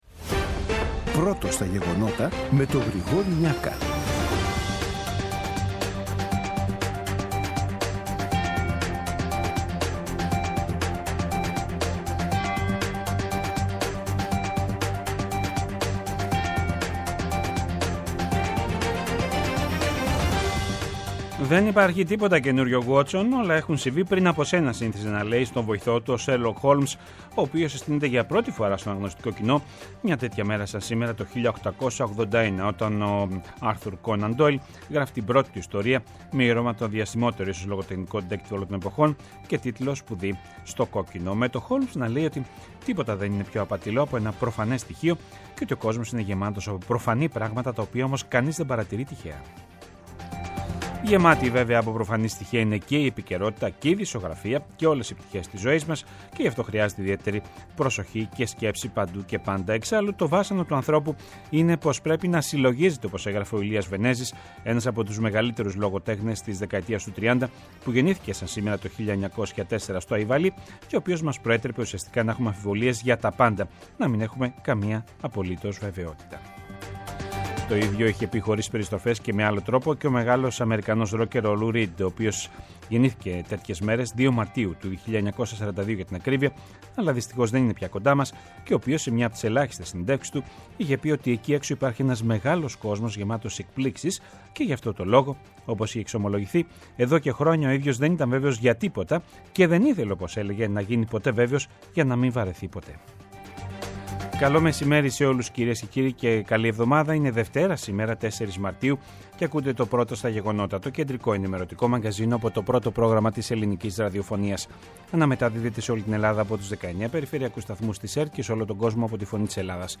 Το κεντρικό ενημερωτικό μαγκαζίνο του Α΄ Προγράμματος, από Δευτέρα έως Παρασκευή στις 14:00. Με το μεγαλύτερο δίκτυο ανταποκριτών σε όλη τη χώρα, αναλυτικά ρεπορτάζ και συνεντεύξεις επικαιρότητας.